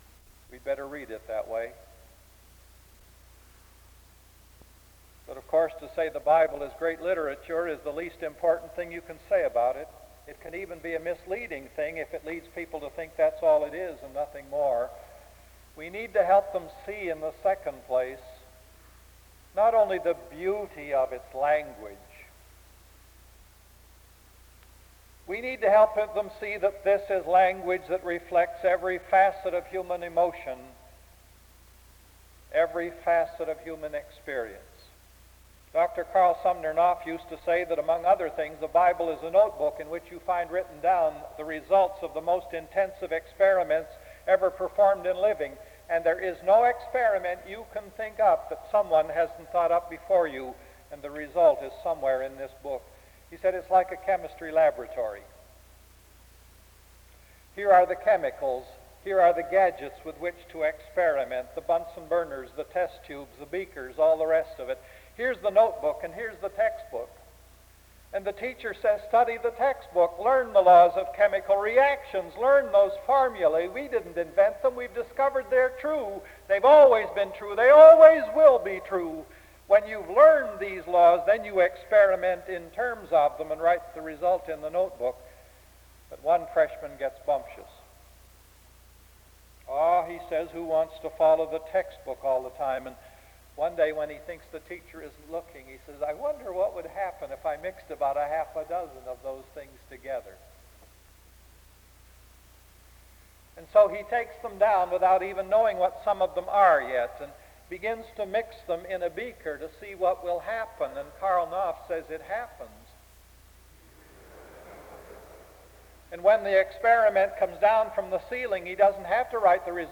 D.C. The service begins with a scripture reading and prayer from 0:00-3:04. An introduction to the speaker is given from 3:09-4:46.